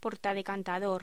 Locución: Portadecantador
voz